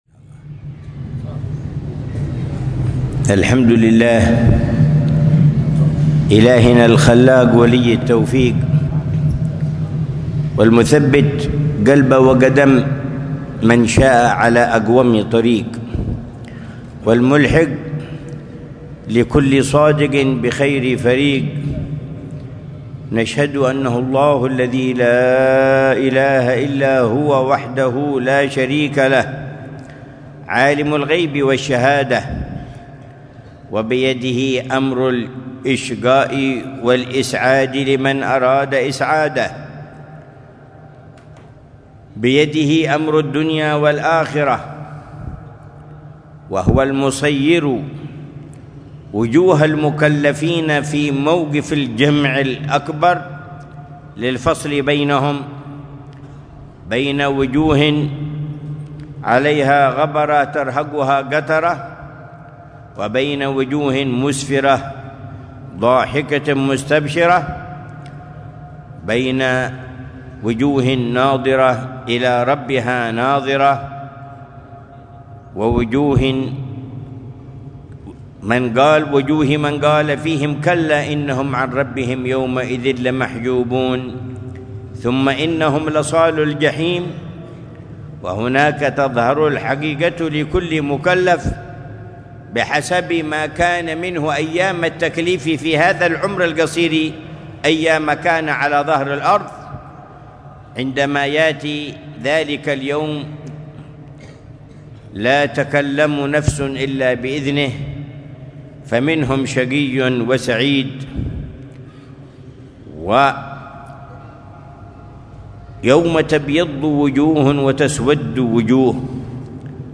محاضرة العلامة الحبيب عمر بن محمد بن حفيظ في حفل اختتام المسابقة الرابعة في كتاب ( الرسالة الجامعة ) وفعاليات حولية الإمام الحبيب أحمد بن زين الحبشي، في منطقة الحوطة في مسجد الجامع، ليلة الخميس 28